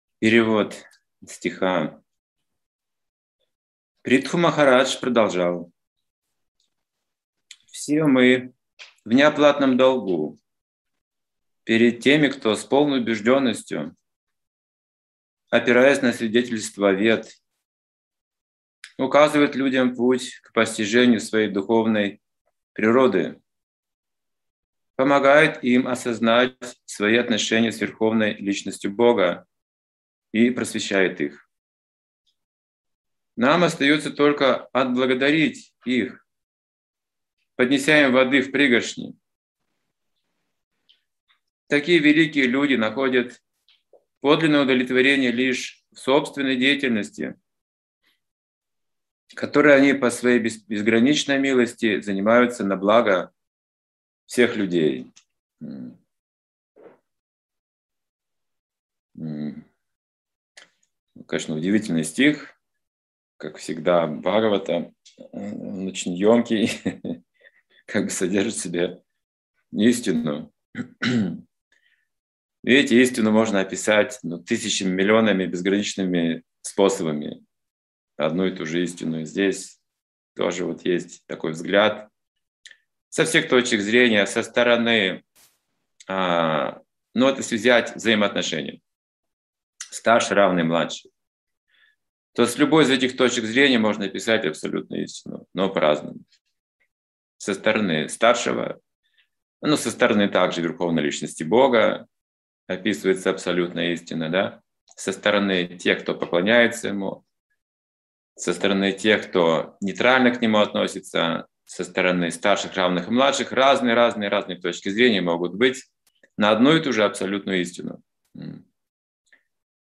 Вопросы и ответы